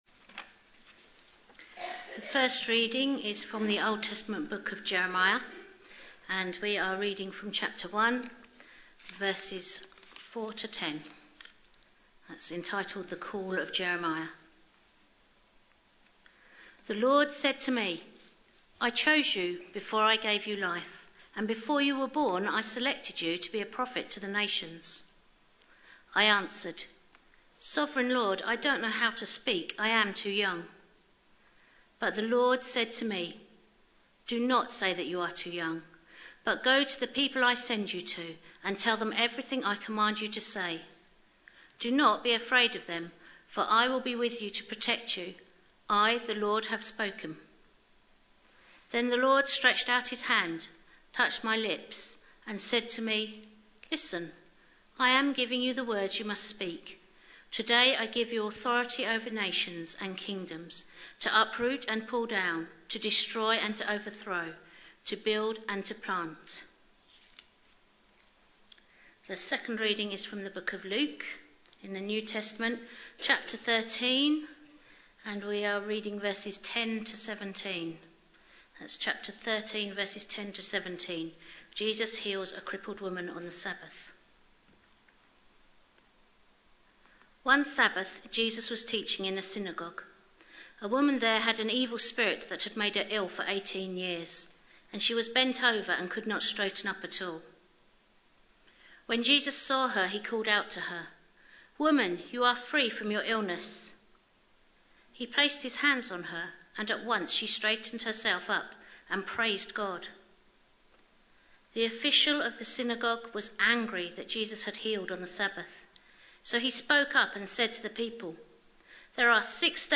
A sermon preached on 31st January, 2016.